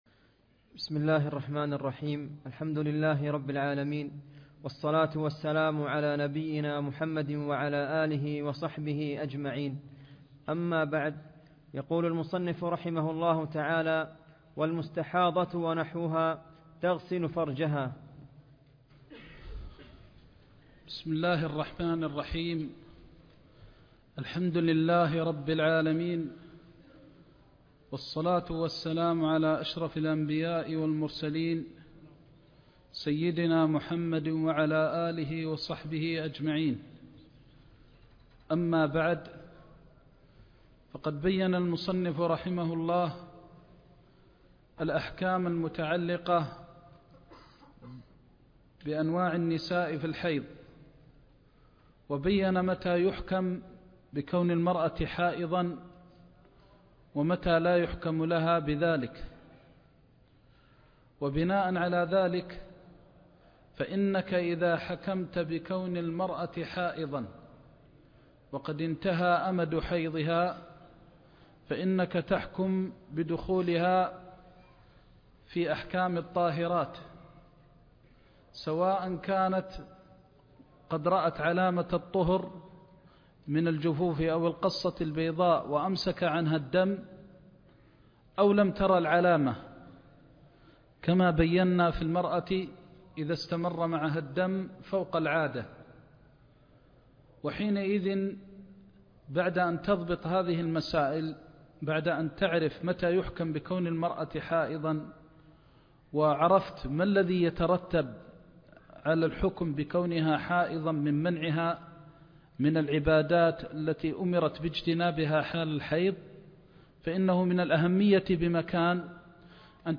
زاد المستقنع كتاب الطهارة (34) درس مكة - الشيخ محمد بن محمد المختار الشنقيطي